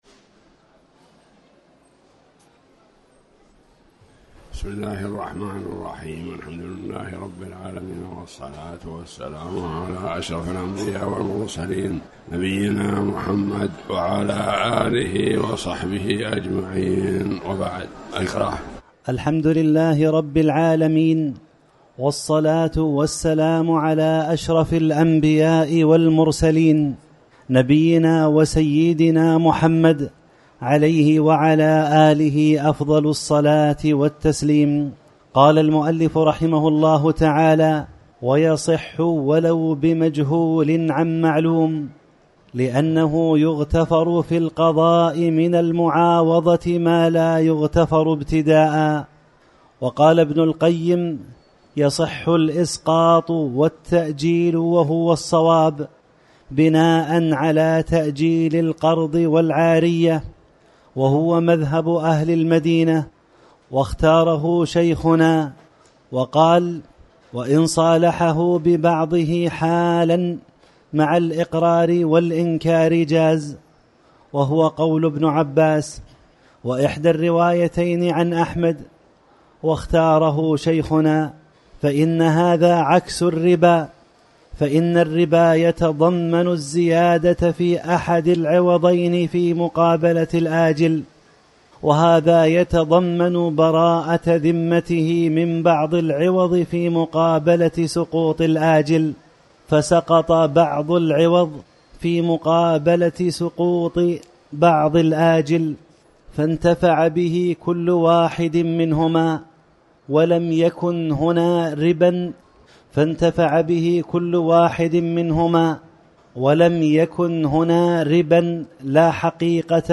تاريخ النشر ٧ جمادى الأولى ١٤٤٠ هـ المكان: المسجد الحرام الشيخ